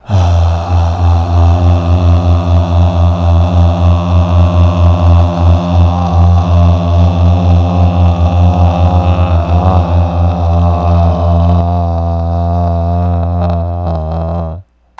Evil Vox | Sneak On The Lot
Vocals Evil, Oscillating, Bumpy